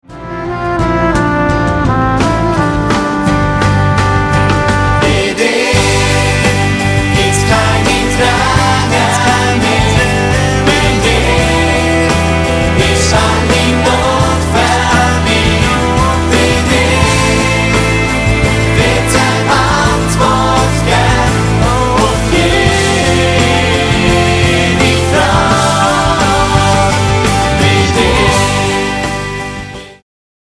Mundart-Worshipsongs